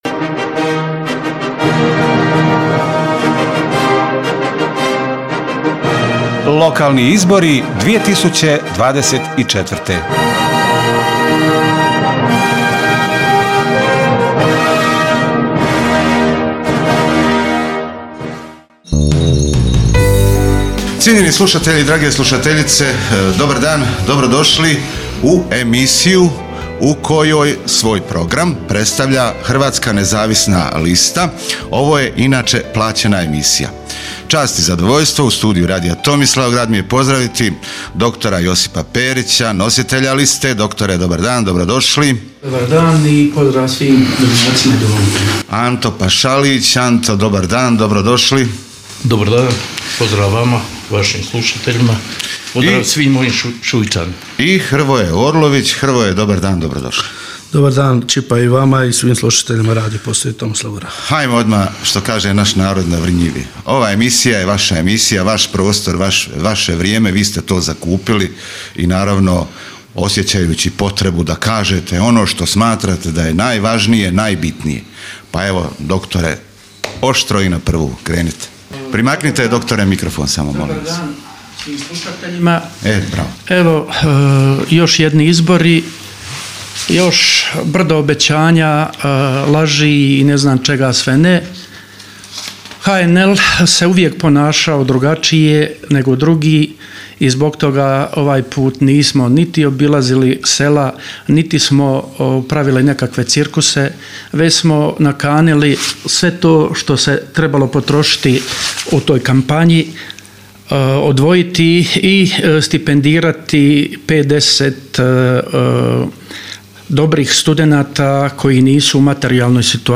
Svoje završno obraćanje i poruku puku Tomislavgrada pred nedjeljne lokalne izbore, u programu Radija Tomislavgrad uputili su iz Hrvatske nezavisne liste.
HNL-Radio-Tomislavgrad.mp3